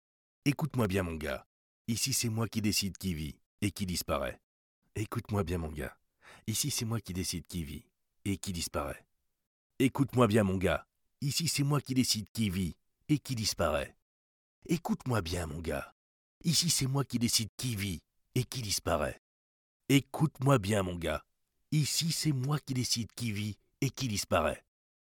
Voix off
Voix mafieux